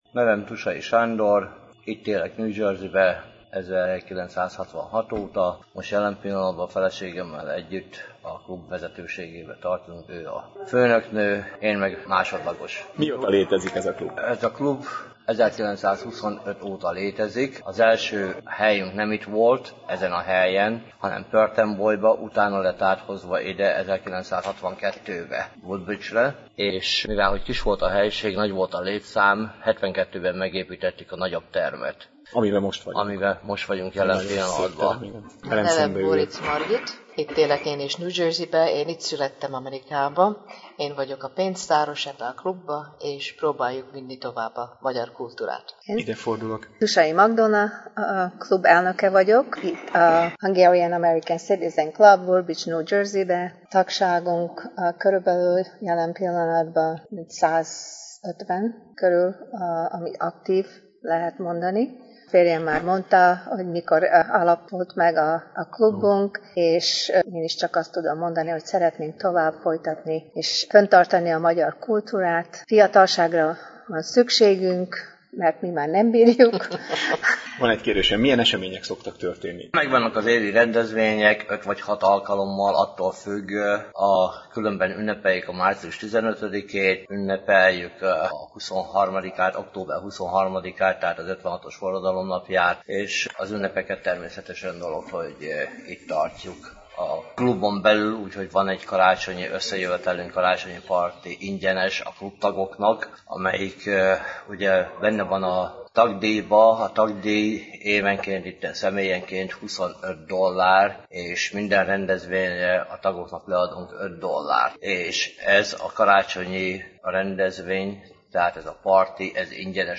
E csapat három tagjával ültem le egy rövid beszélgetésre, azok után, hogy egy sikeres farsangi mulatságot tudhattak maguk mögött. Kedvességüket és közvetlenségüket nagyon köszönjük és kívánunk nekik sok erőt és kitartást az egyre fogyatkozó New York környéki magyar közösségünk összetartásában.